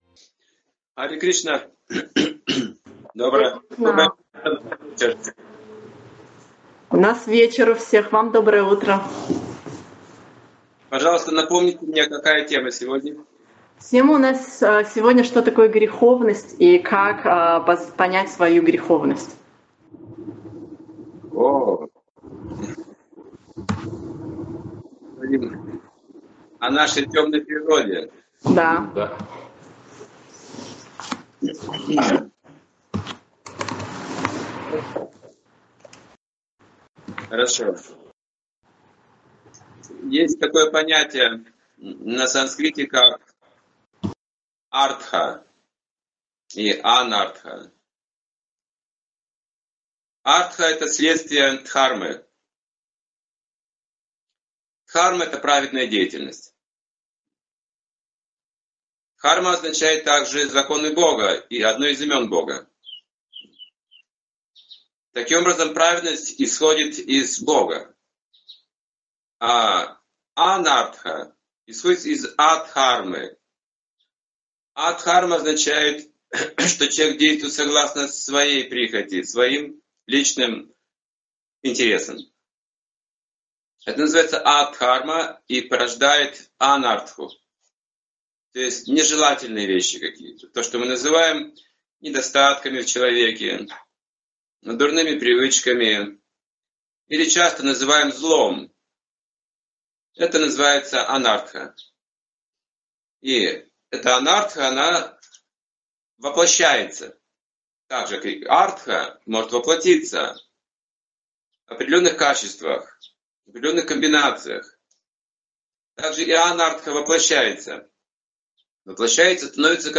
Вебинар Что такое греховность (2015, Алматы)